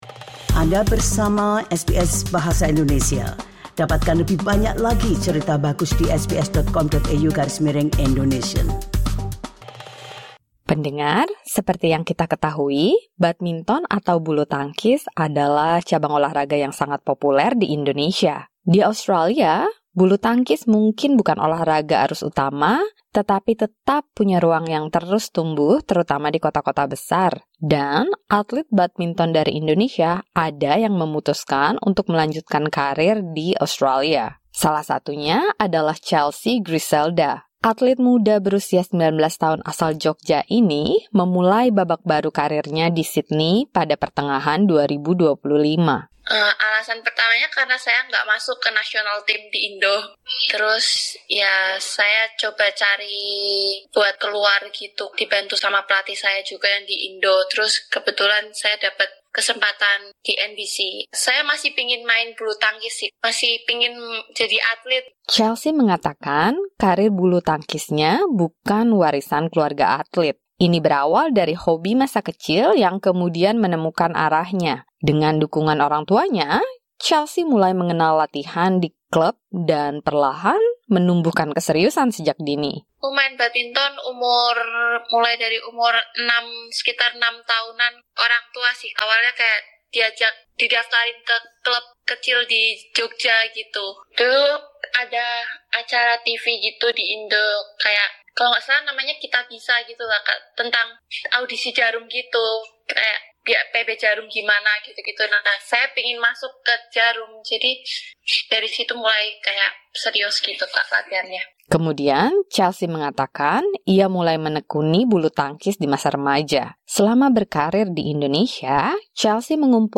Simak perbincangan SBS Indonesian